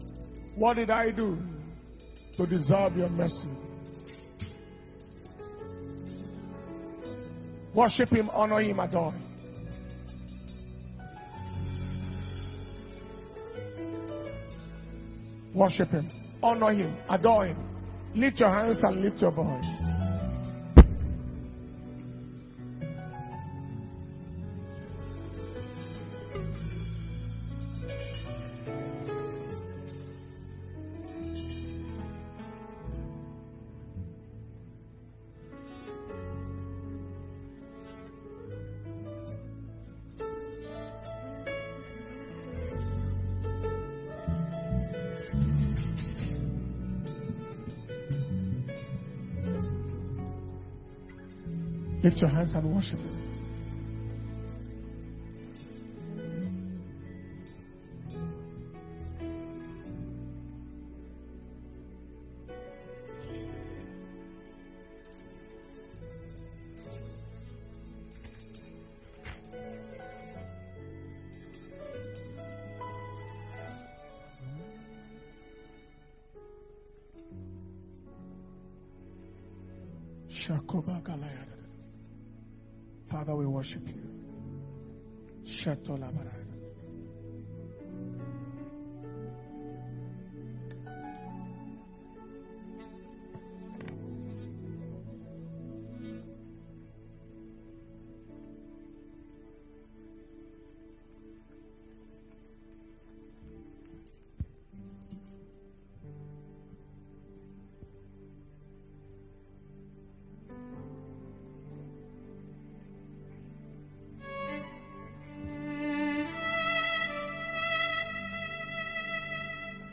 October 2021 Testimonies And Thanksgiving Service – Sunday, 31st October 2021